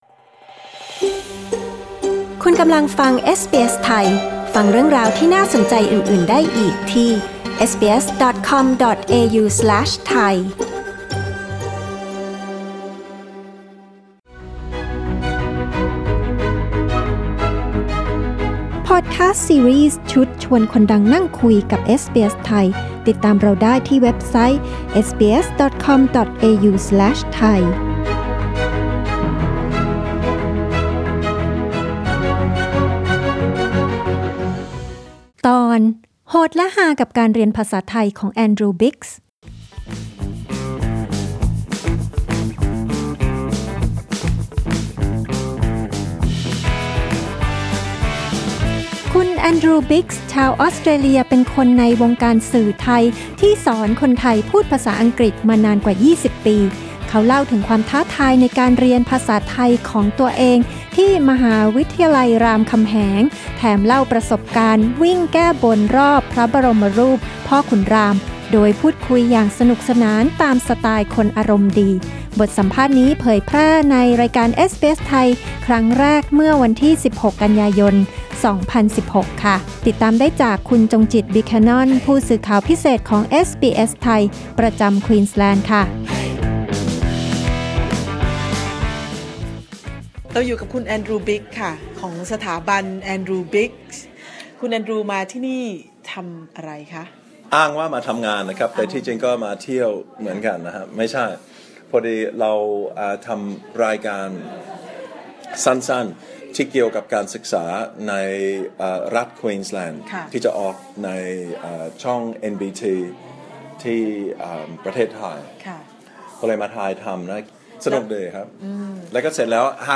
แอนดรูว์ บิ๊กส์ ชาวออสเตรเลีย เป็นคนในวงการสื่อไทยที่สอนคนไทยพูดภาษาอังกฤษมานานกว่า 20 ปี เขาเล่าถึงความท้าทายในการเรียนภาษาไทยของเขา ที่มหาวิทยาลัยรามคำแหง แถมเล่าประสบการณ์วิ่งแก้บนรอบพระบรมรูปพ่อขุนรามฯ โดยพูดคุย อย่างสนุกสนานตามสไตล์คนอารมณ์ดี บทสัมภาษณ์นี้เผยแพร่ในรายการเอสบีเอส ไทย ครั้งแรกเมื่อ 16 ก.ย. 2016 นี่เป็นหนึ่งเรื่องราวจากพอดคาสต์ ซีรีส์ ชุด “ชวนคนดังนั่งคุย” ของเอสบีเอส ไทย